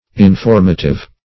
Informative \In*form"a*tive\, a.